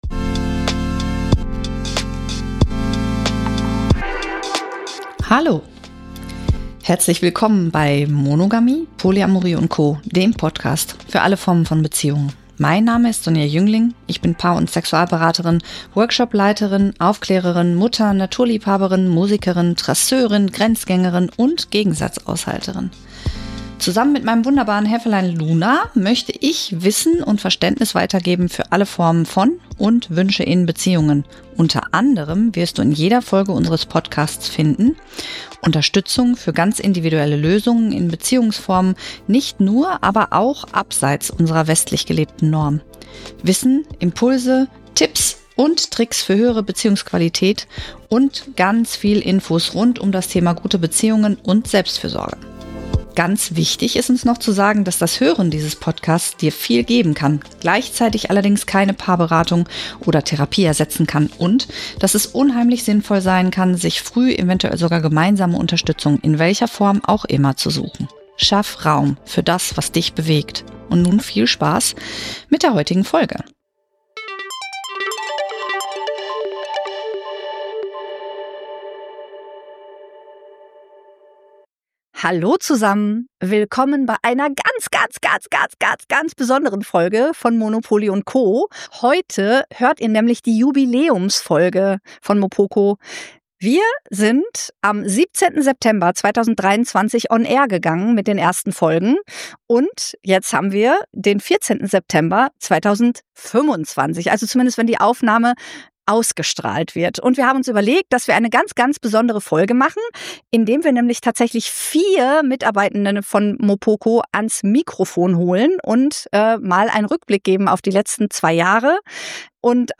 Ihr habt das Wort: Wundervolle Sprachnachrichten und Feedbacks aus der Community.